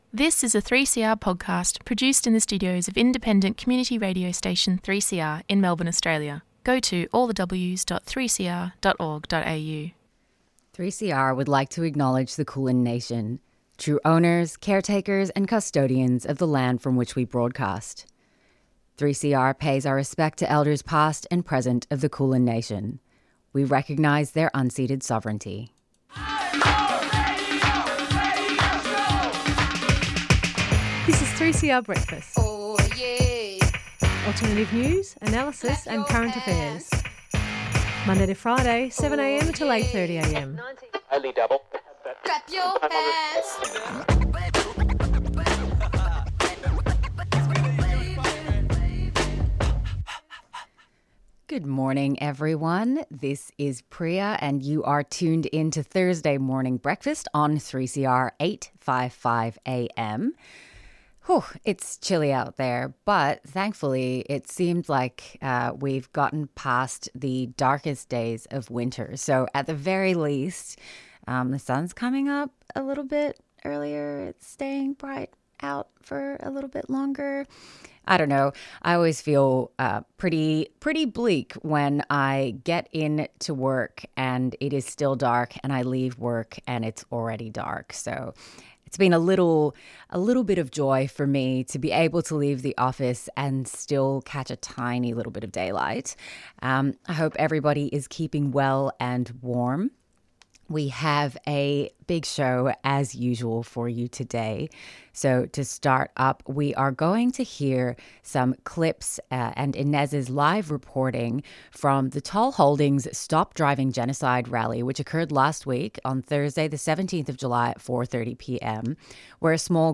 Acknowledgement of Country News Headline